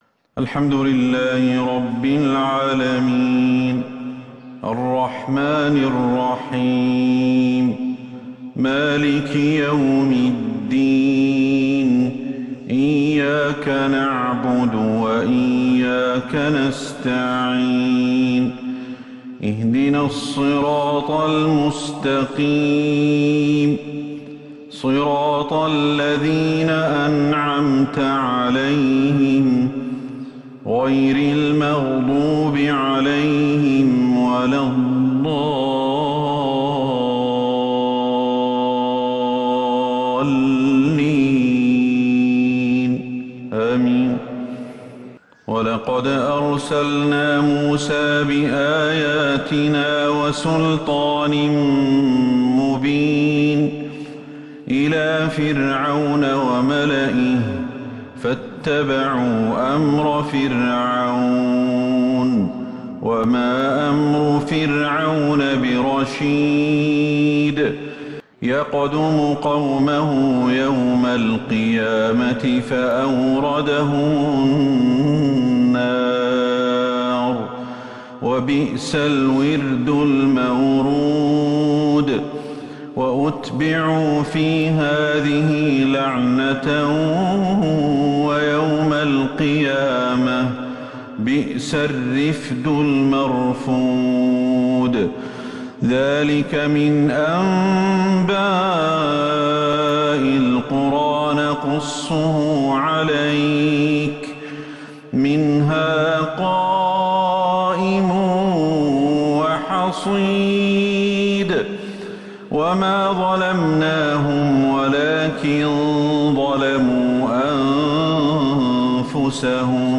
فجر الثلاثاء 29 جمادى الآخرة 1443هـ ما تيسر من سورة {هود} > 1443 هـ > الفروض